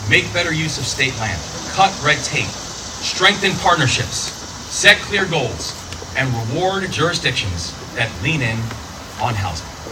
Moore said permitting will be made simpler among other steps to be taken in his executive order…